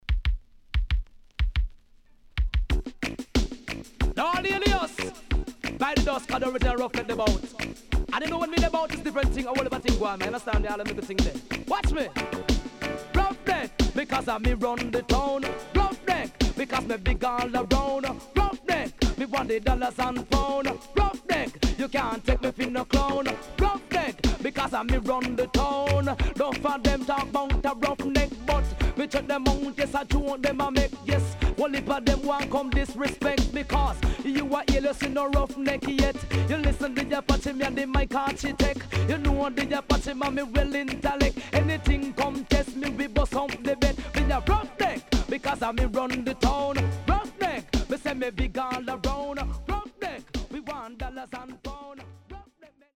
HOME > LP [DANCEHALL]
SIDE B:少しチリノイズ入りますが良好です。盤面は所々うすいこまかい傷あり。